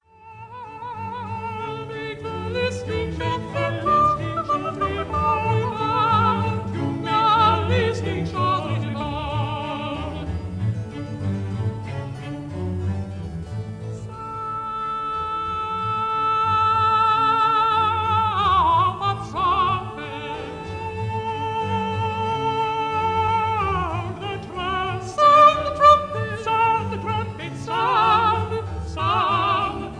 countertenor